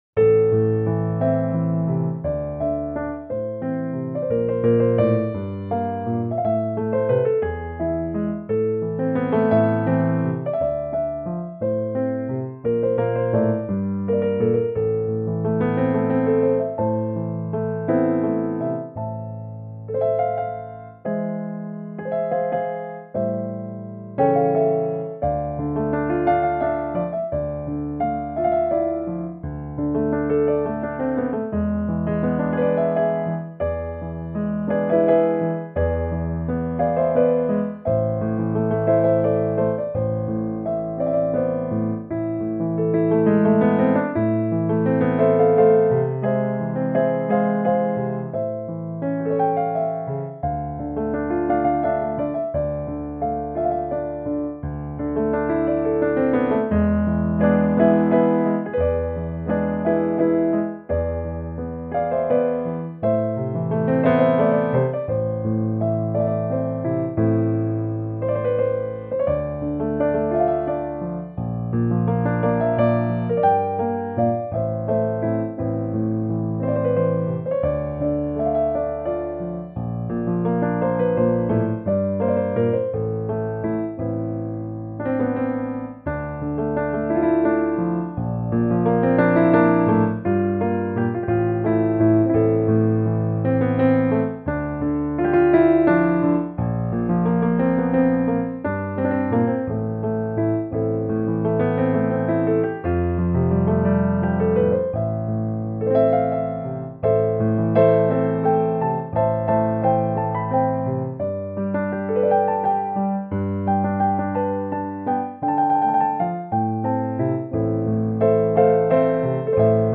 Solo Piano